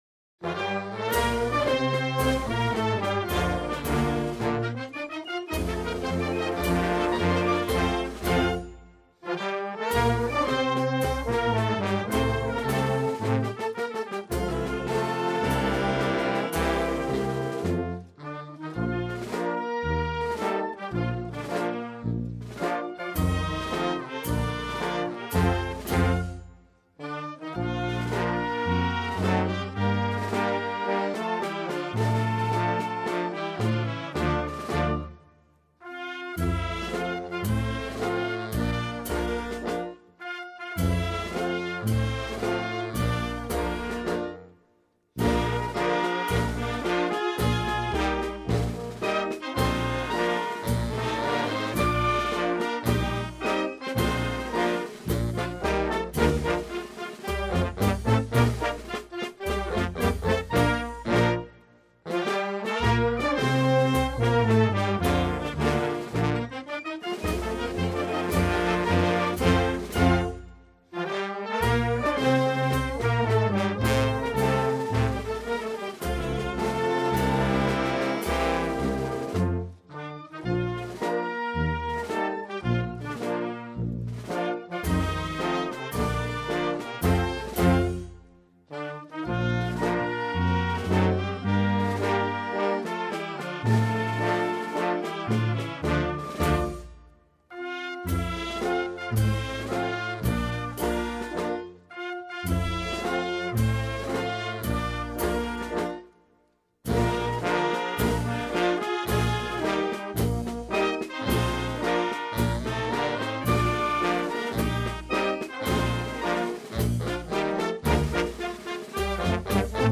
riogradesul_anthem.mp3